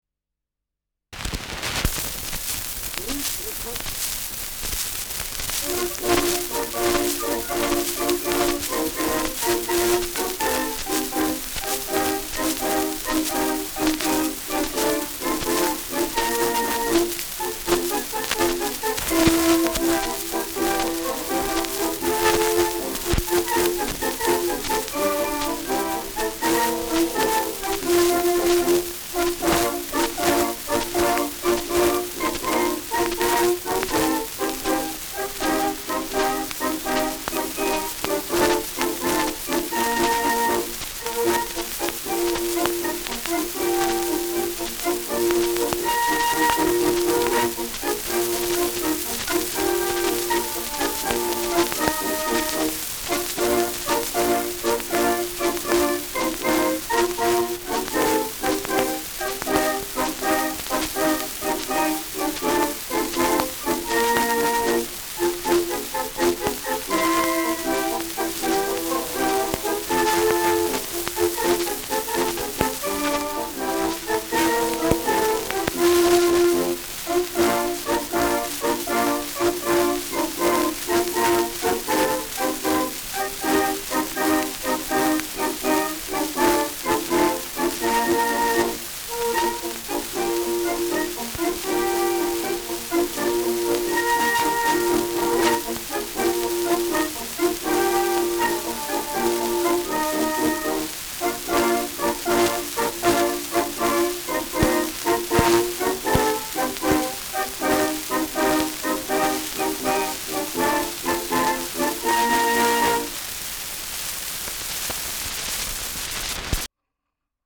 Schellackplatte
Abgespielt : Erhöhtes Grundrauschen : Nadelgeräusch : Gelegentlich leichtes Knacken
Militärmusik des k.b. Leib-Regiments, München (Interpretation)
[München] (Aufnahmeort)